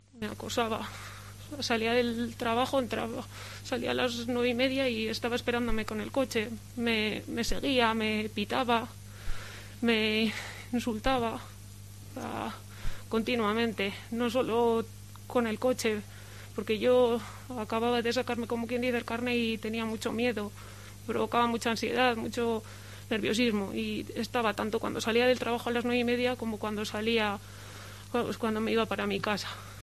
con voz temblorosa